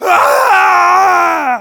Voice file from Team Fortress 2 German version.
Demoman_paincrticialdeath04_de.wav